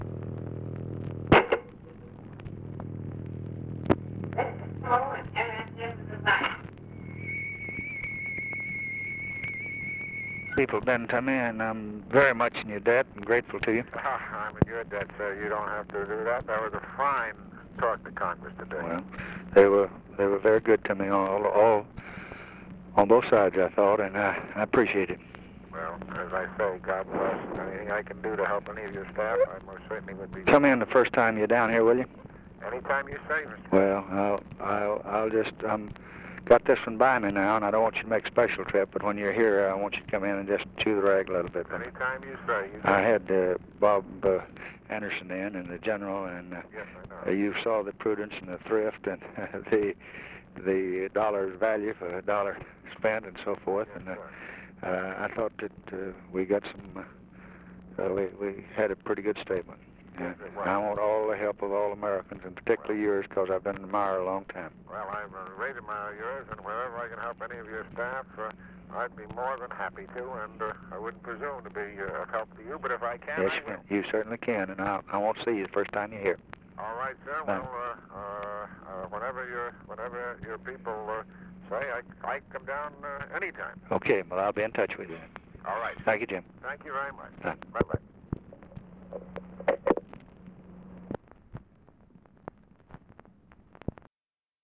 Conversation with JIM HAGERTY, November 27, 1963
Secret White House Tapes